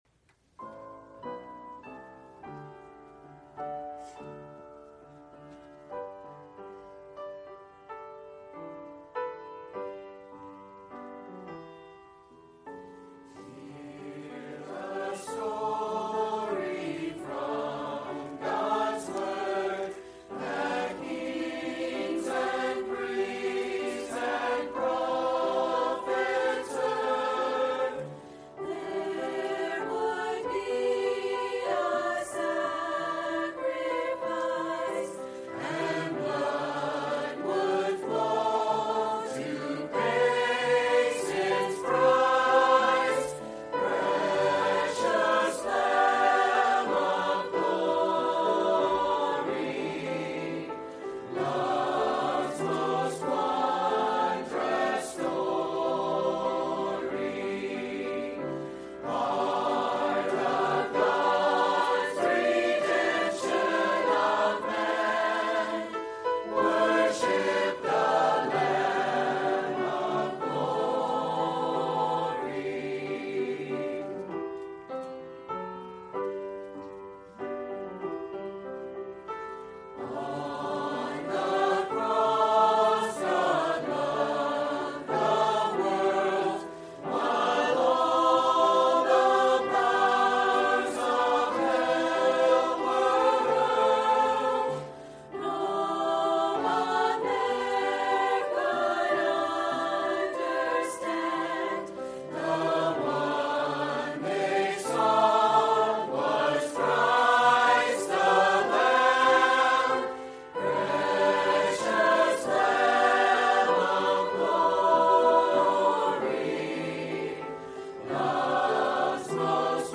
Sermons – Pioneer Baptist Church of Citrus Heights, CA
Standalone Sunday Messages at PBC